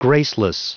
Prononciation du mot graceless en anglais (fichier audio)
Prononciation du mot : graceless